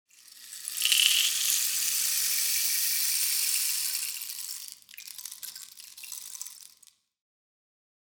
30 Slides covering Rainmakers, Bongo, Conga, and Various other random slide sounds
BW_x_JDR_DOP_Rainmaker_Slide_07.mp3